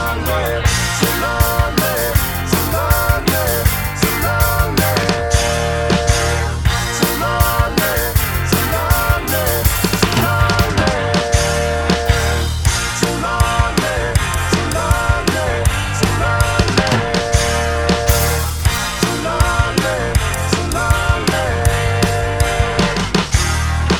Two Semitones Down Pop (1980s) 4:47 Buy £1.50